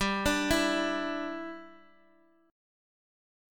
Listen to G56 Chord {15 17 14 X X X} strummed